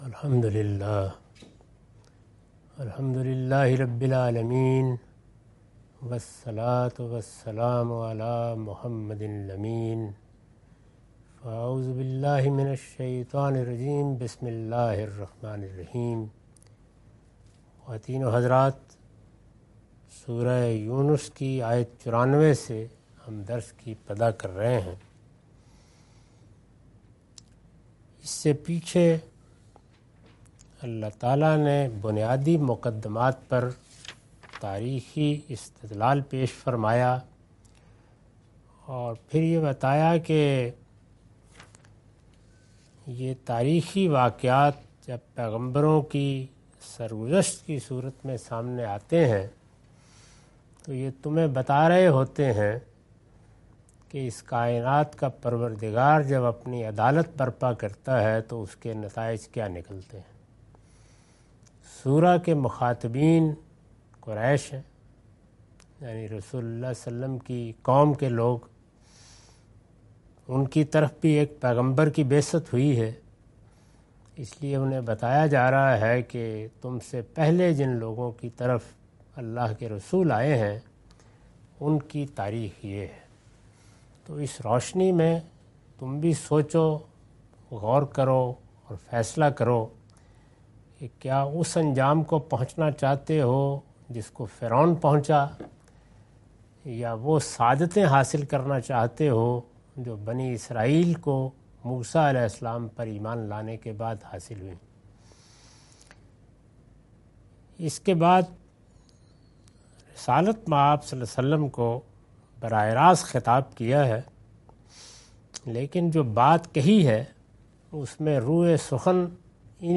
Surah Yunus- A lecture of Tafseer-ul-Quran – Al-Bayan by Javed Ahmad Ghamidi. Commentary and explanation of verses 94-107.